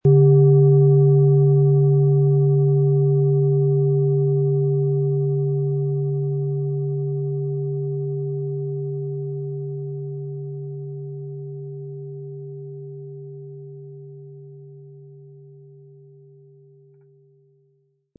Planetenton
Nach uralter Tradition von Hand getriebene Klangschale.
Im Sound-Player - Jetzt reinhören können Sie den Original-Ton genau dieser Schale anhören.
Den passenden Klöppel erhalten Sie umsonst mitgeliefert, er lässt die Schale voll und wohltuend klingen.
MaterialBronze